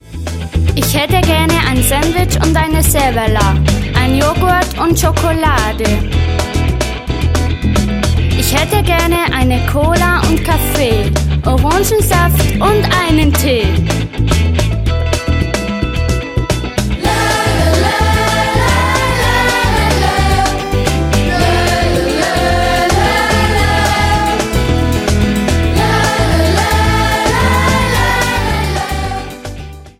Lernlieder